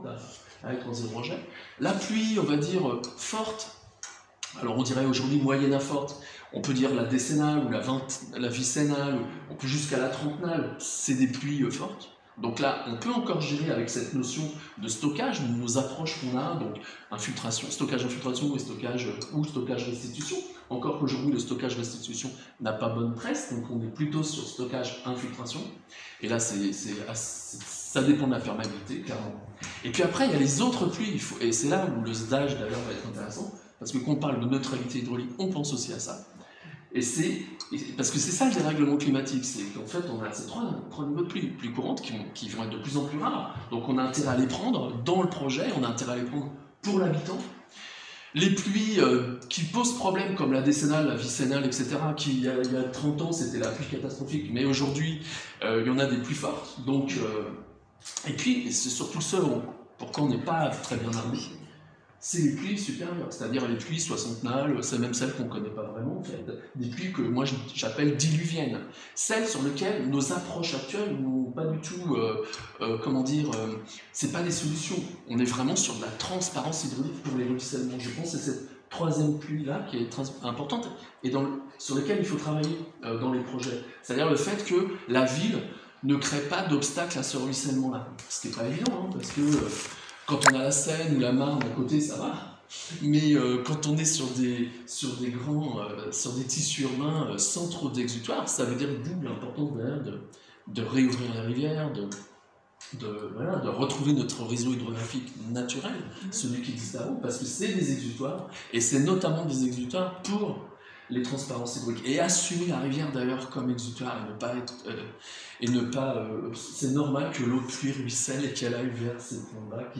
Cette dernière matinée, ouverte aux services techniques, a permis d’approfondir la thématique de la gestion à la source des eaux pluviales, grâce entre autres , à la présentation de plusieurs cas d’études concrets. Retrouvez les présentations et documents présentés : Support de présentation Extrait audio de la séance - 1/3 Extrait audio de la séance - 2/3 Extrait audio de la séance - 3/3